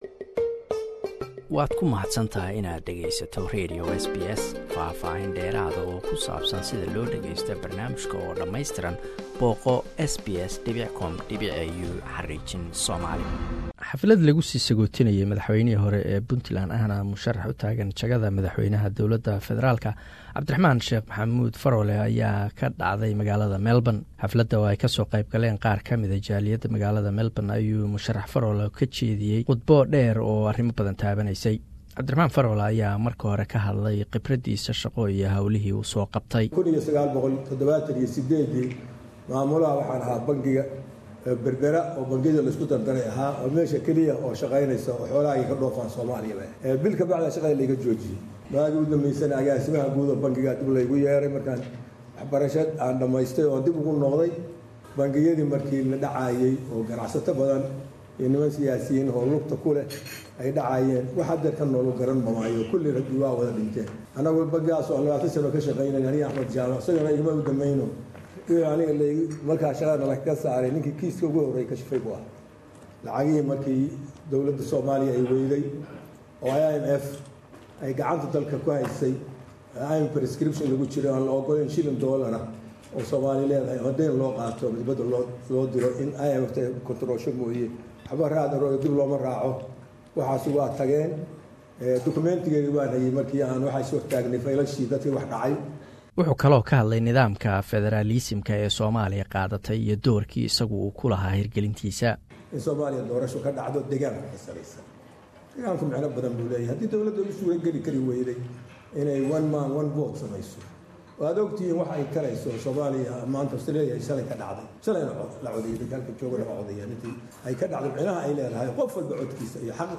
Abdirahman Faroole is a former Puntland President and Federal Presidential aspirant. He delivered speech at a ceremony in Melbourne as he prepares for his campaign